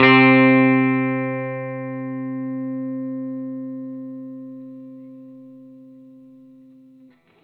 R12 NOTE  C.wav